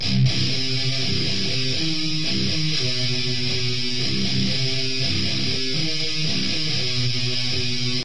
描述：我想大多数是120bpm，不确定
Tag: 1 吉他 铁杆 金属 石头 RYTHEM rythum 捶打